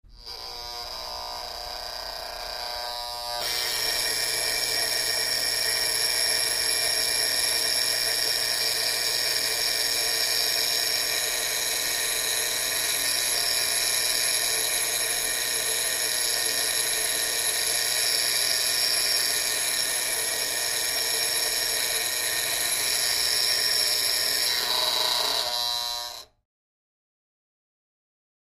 Alarm Oven Timer Old 1; On Surge, Buzzer Type Runs Ratty, Off, Close Perspective